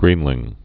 (grēnlĭng)